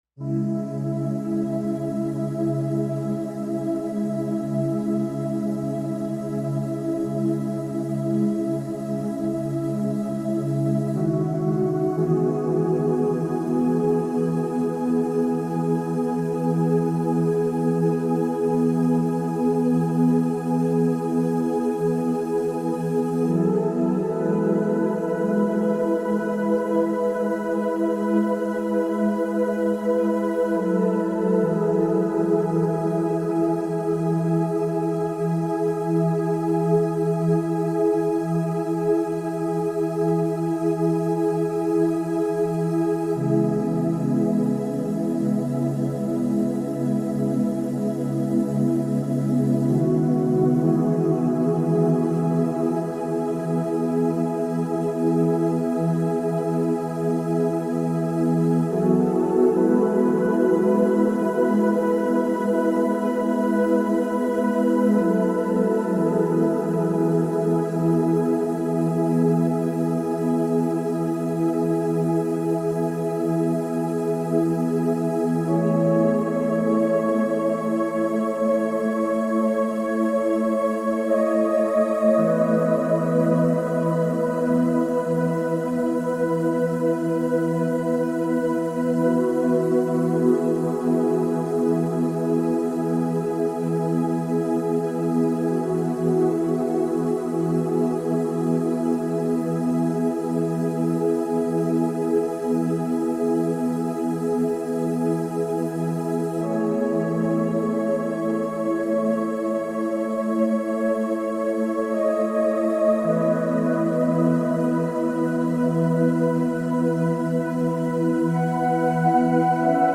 Energie Zentrum Bessere Sehkraft, Augenheilung & Emotionen reinigen | Meditation